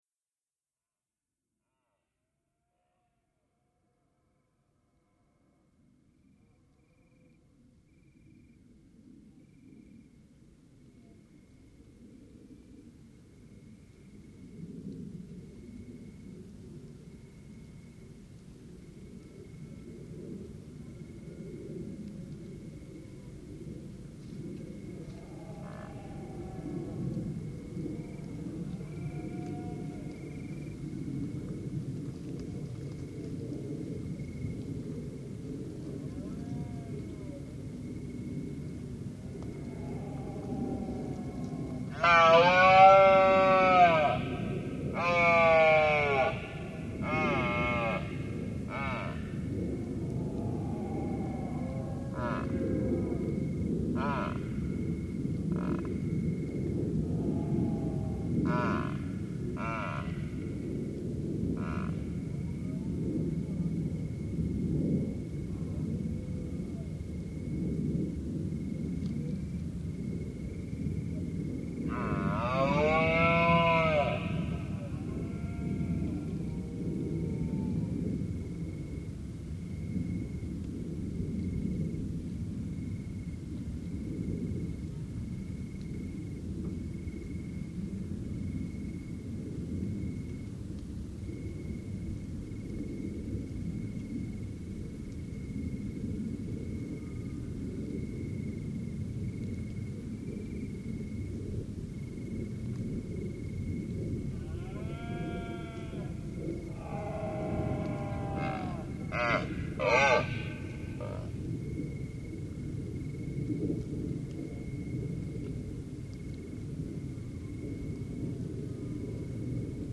In Serra da Lousã, in the interior of Portugal, between the months of September and November, the population of deer that live there all year round make themselves heard in their seasonal roar, a sonorous landmark of the breeding season, made up of guttural bellows. Autumnal nature in its sonorous splendour...